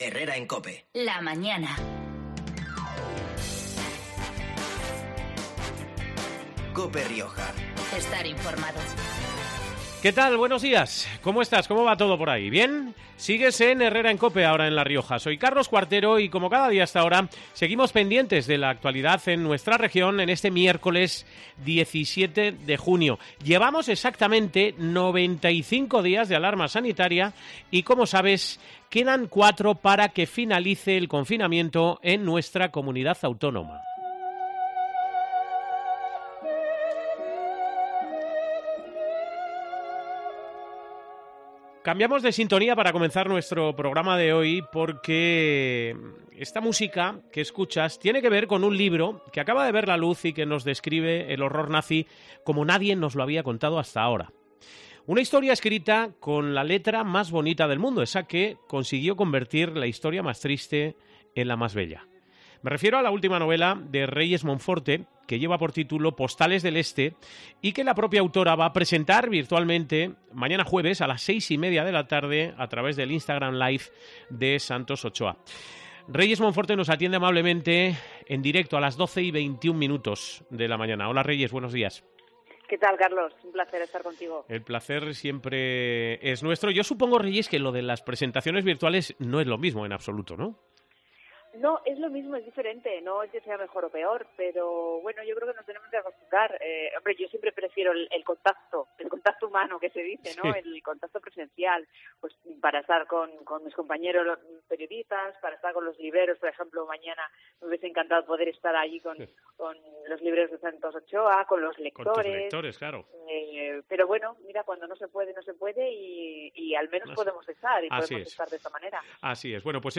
La propia autora ha pasado este mediodía por los micrófonos de COPE Rioja no sólo para describrinos algunos de los personajes reales que sostienen la trama, sino para invitarnos a la presentación virtual de la novela que tendrá lugar este jueves, 18 de junio , a las 18:30 horas , en el Instagram Live de Santos Ochoa .